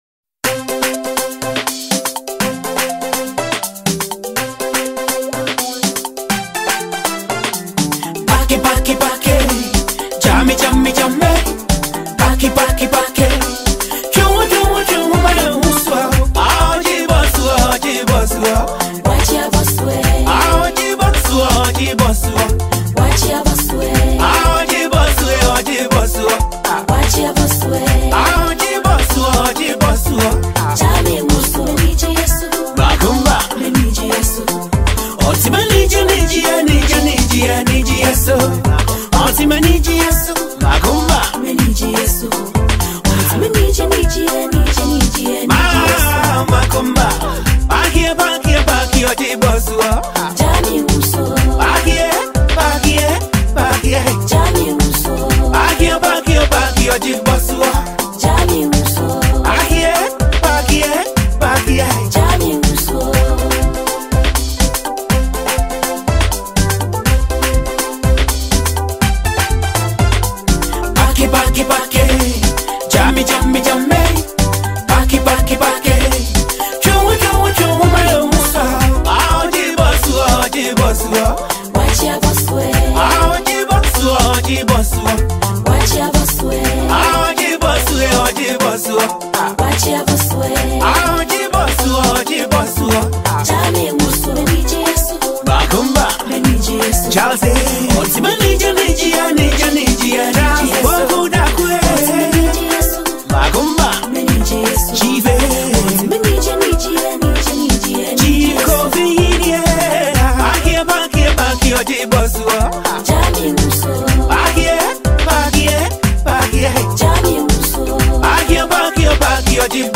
smooth vocals, and signature highlife sound